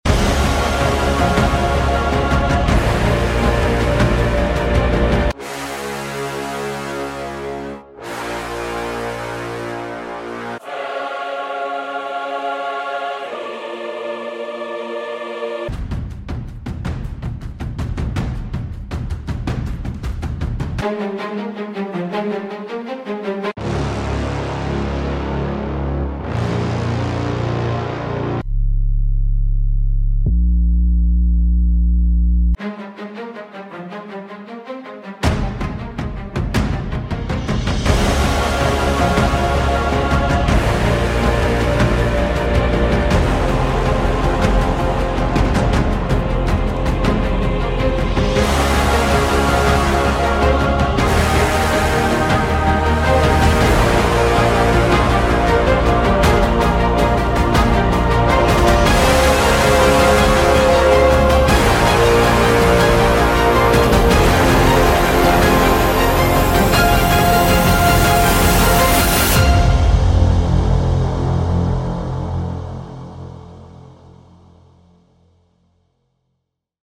heroic action music